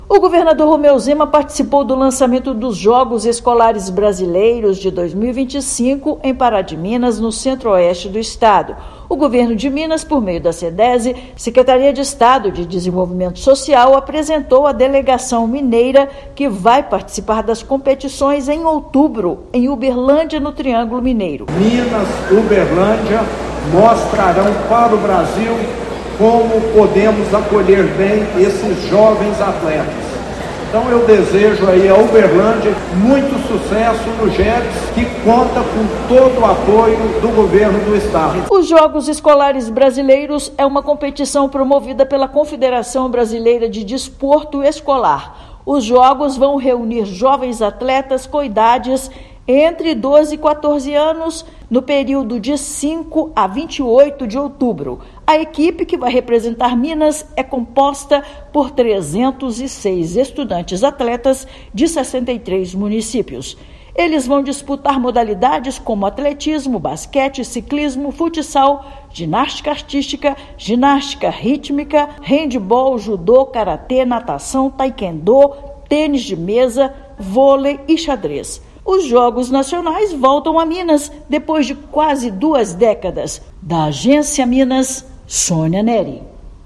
Uma das maiores competições esportivas do país volta a ser realizada no estado depois de 19 anos. Ouça matéria de rádio.